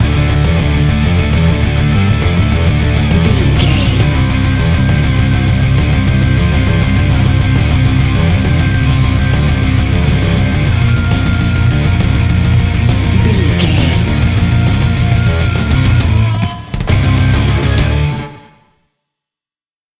Fast paced
Aeolian/Minor
Fast
driving
energetic
bass guitar
electric guitar
drums